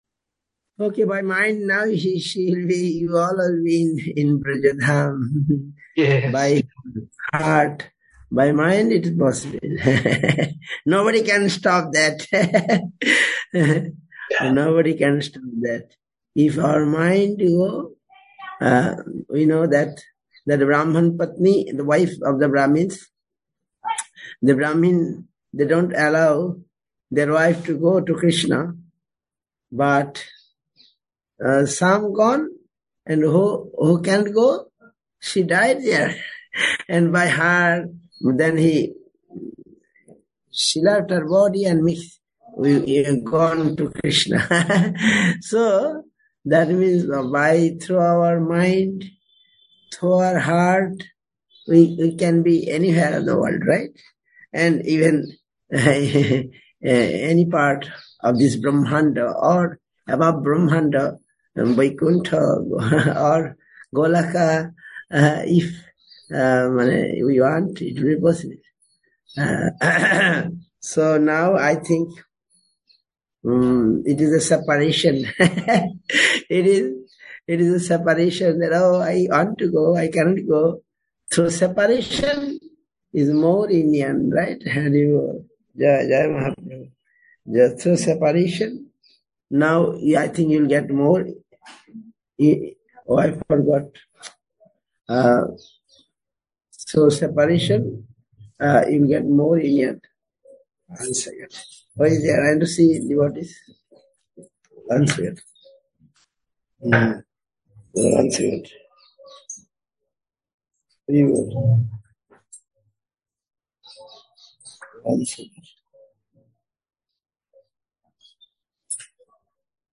Harikhatha
India, Radha Kund Dham, SREE CAITANYA SRIDHAR SEVA ASHRAM.